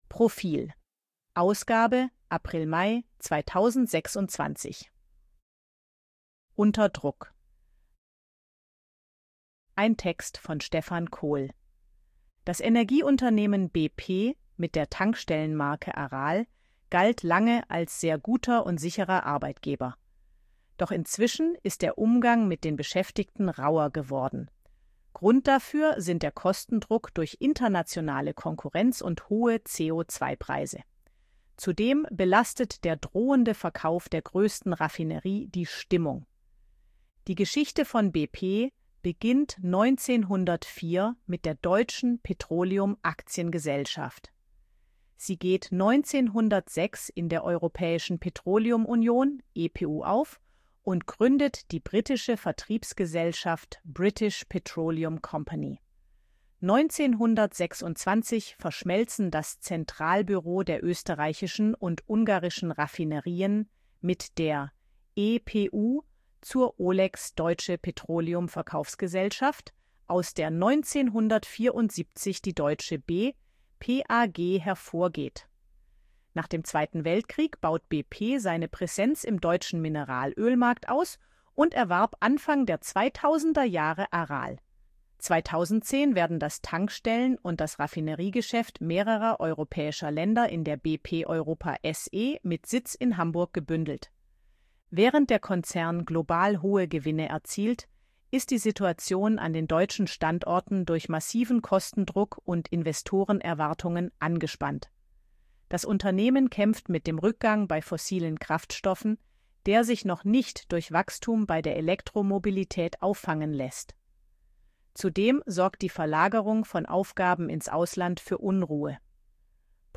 ElevenLabs_262_KI_Stimme_Frau_AG-Check.ogg